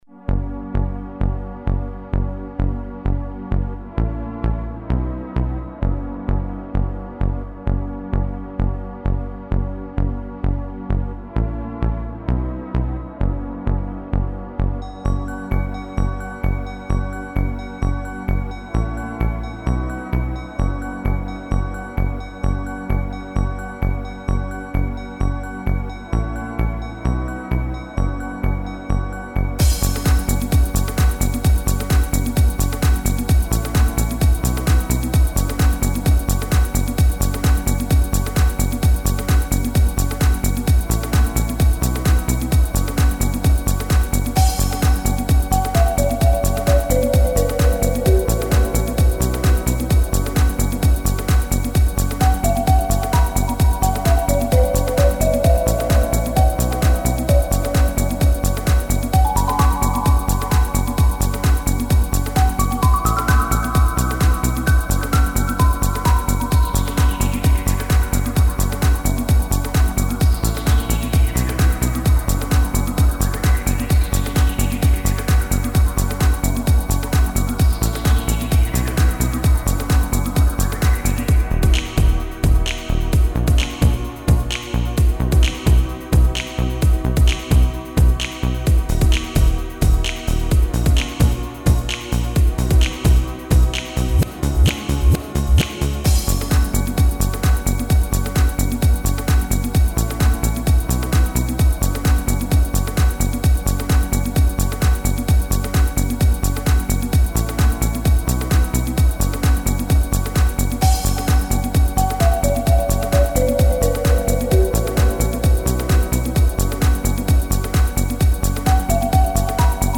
Ocean of tears Reines Instrumentalstück das als Hintergrundmusik für ein selbstprogrammiertes Computerspiel entstanden ist